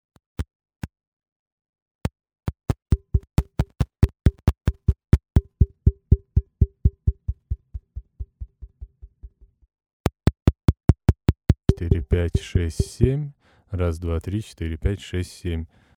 Легонько постучал пальцем по мику и услышал легкие щелчки.
Так пальцем еще немного постучал и начал пробиваться звук, иногда он проявлялся, но потом в простое опять пропадал. От постукивания снова оживал.
Записал аудио фрагмент Вложения mic-problem.mp3 mic-problem.mp3 624 KB · Просмотры: 478